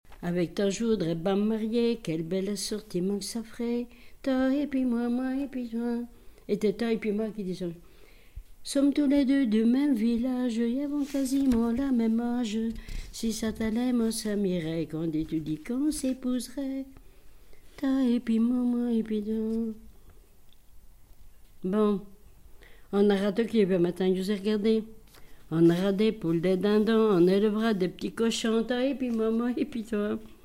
Chanson extraite d'un recueil manuscrit
Pièce musicale inédite